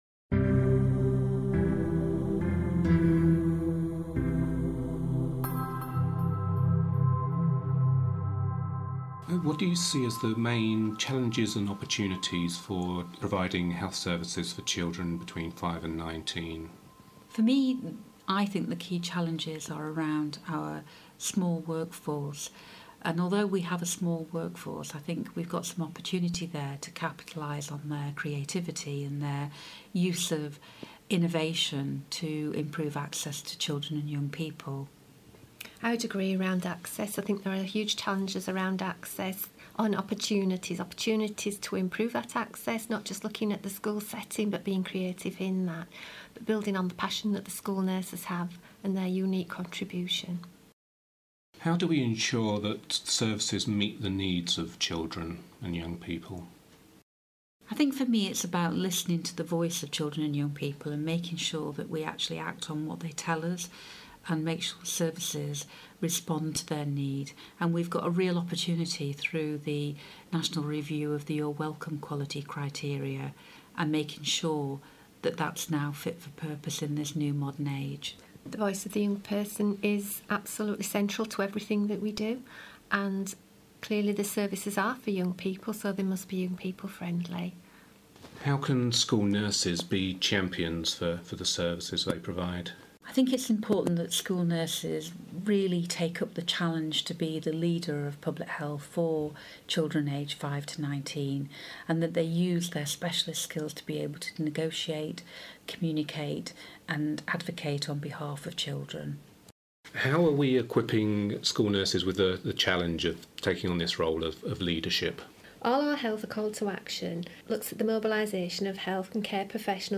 Brief Q&A